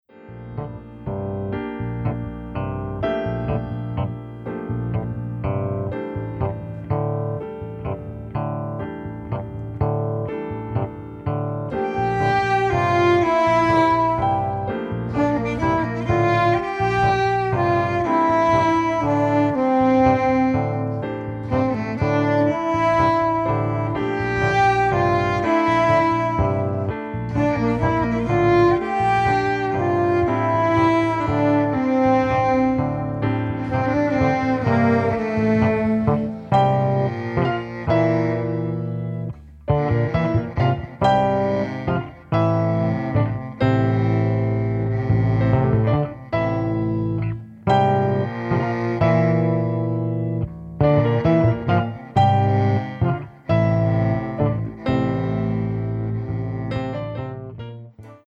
saxophones
piano.
Australia’s only electric viola da gamba ensemble
Classical